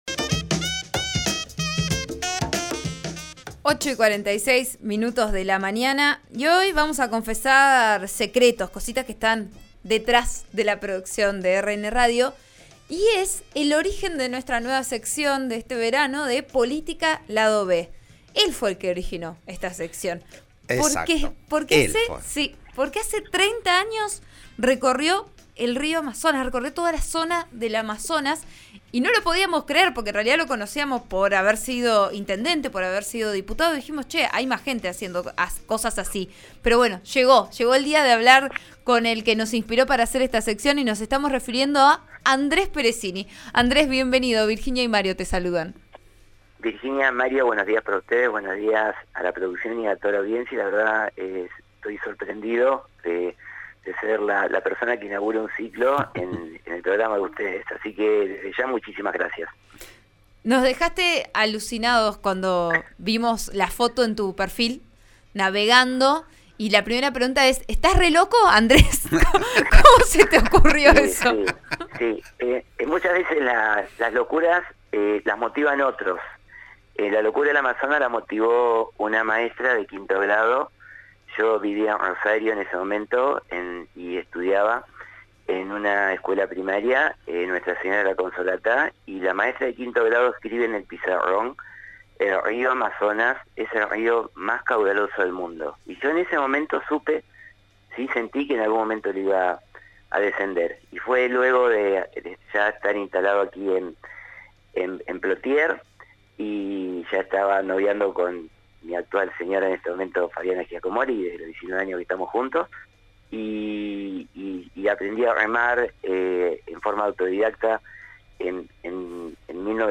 Peressini anunció, en diálogo con «Vos A Diario» (RN RADIO 89.3), que el inicio de su travesía será el lunes, desde Plottier.